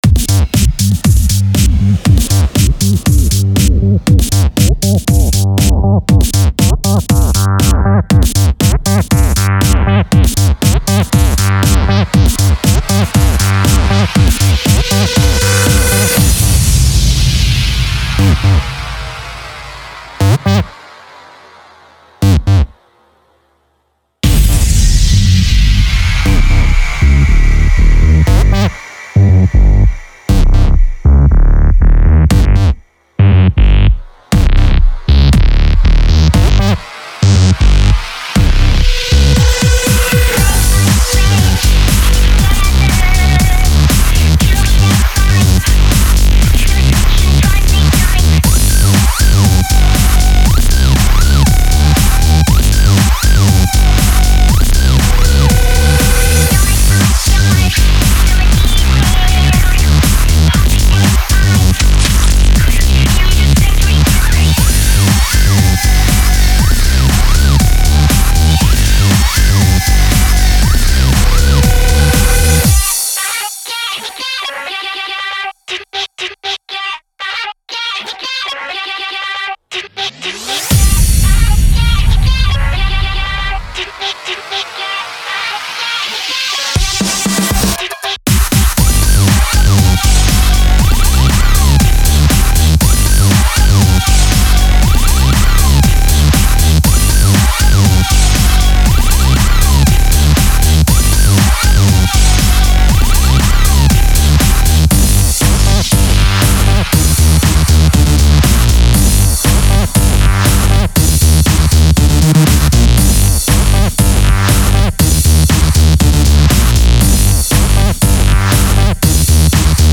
429031 song